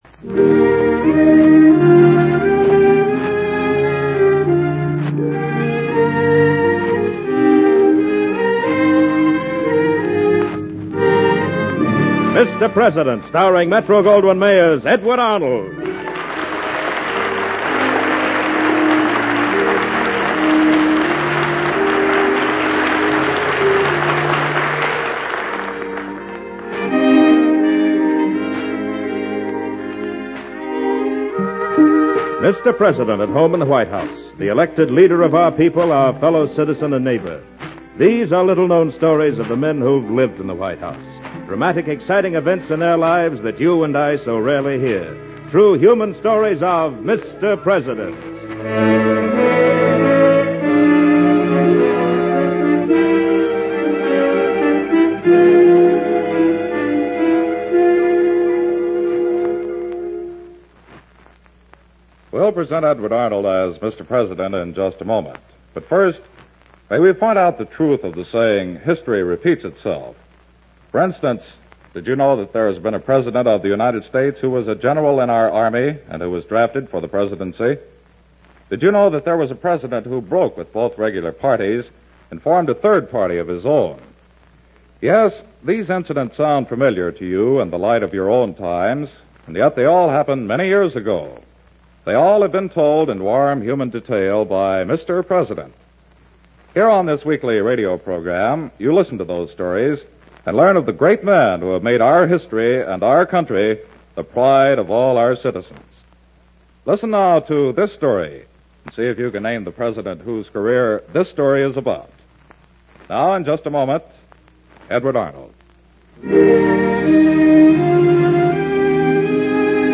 Mr. President, Starring Edward Arnold